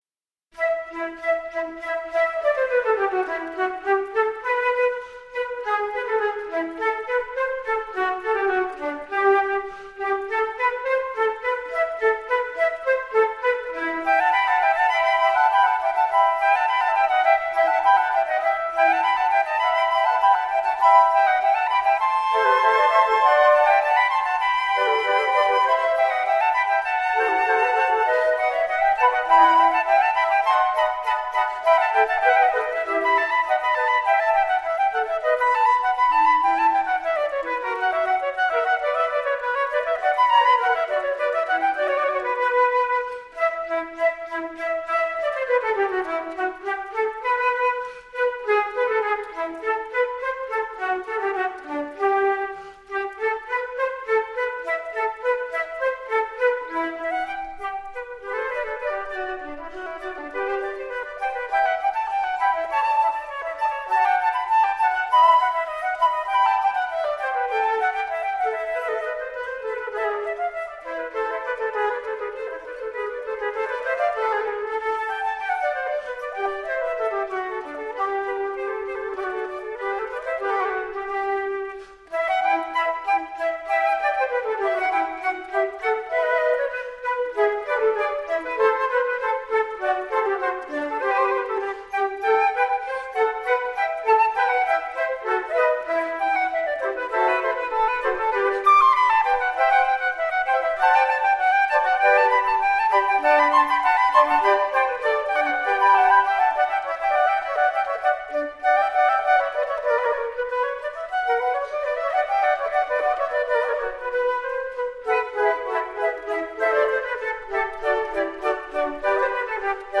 Classical, Baroque, Instrumental
Flute